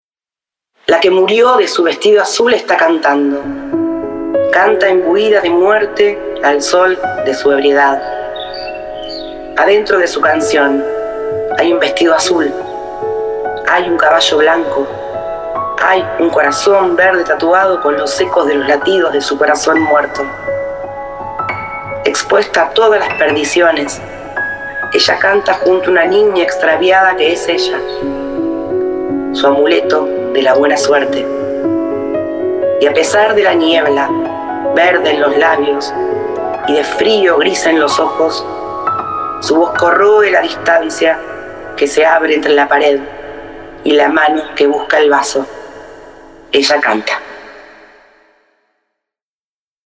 Música y edición